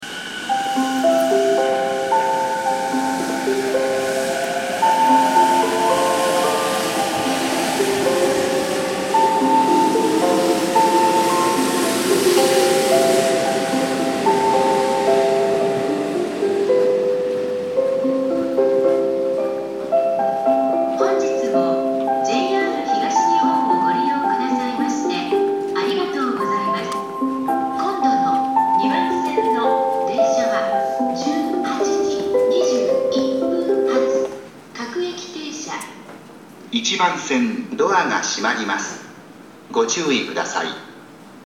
北小金駅　Kita-Kogane Station ◆スピーカー：ユニペックス小丸型
1番線発車メロディー